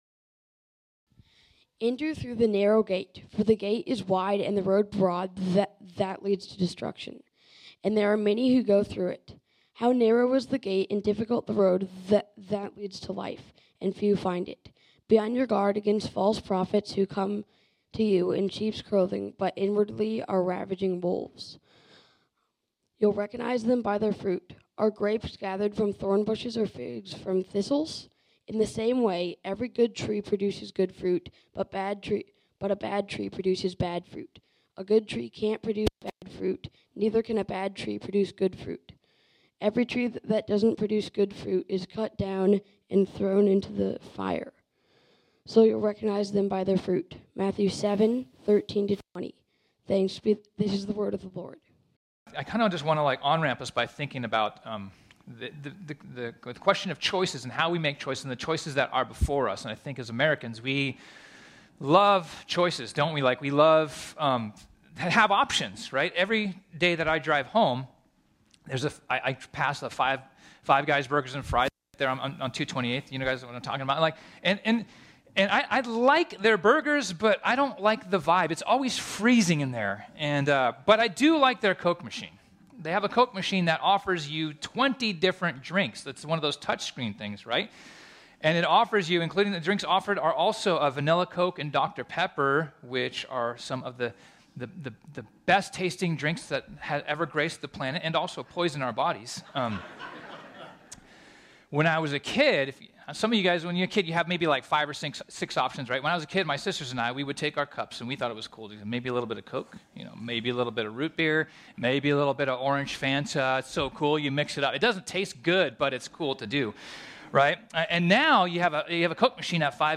” our sermon series on the Gospel of Matthew.